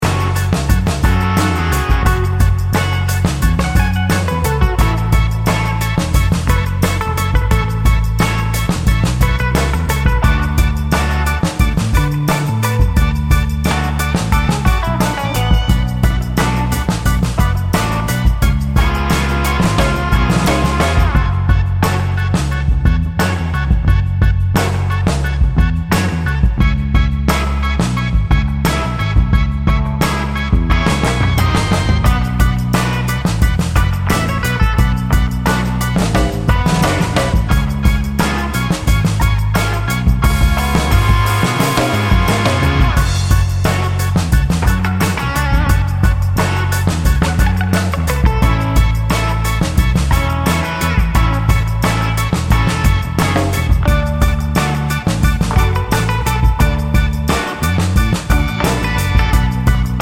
Instrumental Cut Down Pop (1970s) 3:00 Buy £1.50